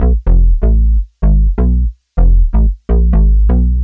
cch_bass_nasty_125_Bm.wav